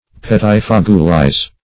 Search Result for " pettifogulize" : The Collaborative International Dictionary of English v.0.48: Pettifogulize \Pet`ti*fog"u*lize\, v. i. To act as a pettifogger; to use contemptible tricks.